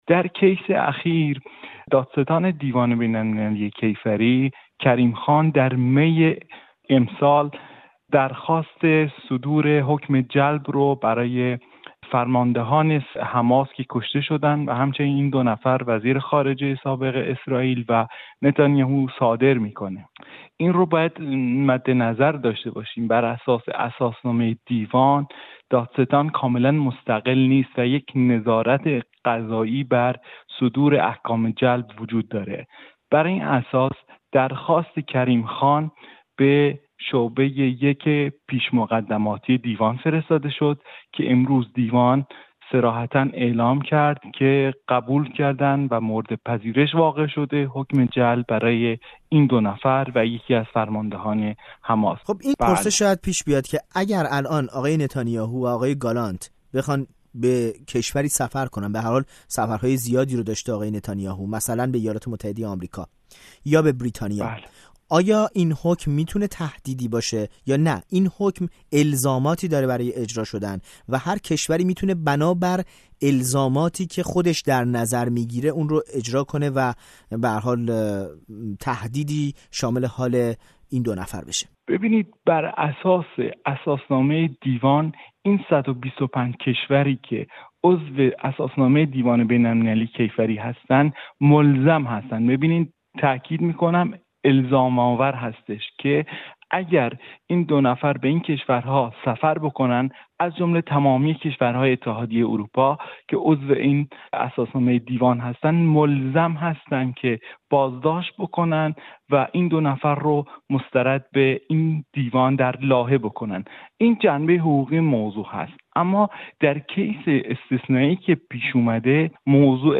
با برنامه خبری-تحلیلی ایستگاه۱۹ رادیو فردا در این زمینه گفت‌وگو کرده است.